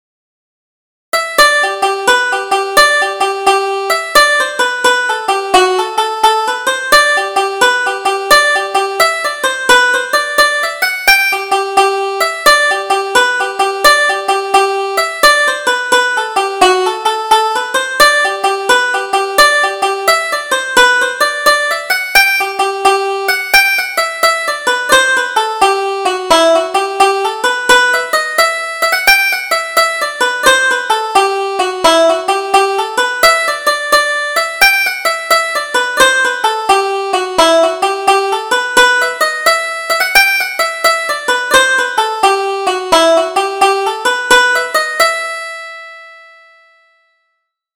Double Jig: Rory O'More